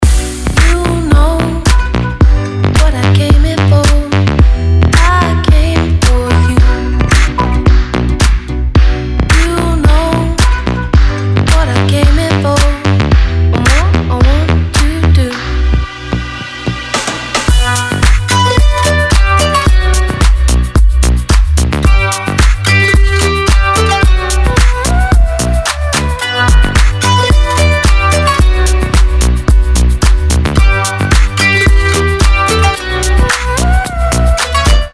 • Качество: 320, Stereo
красивые
женский вокал
deep house
dance
club
струнные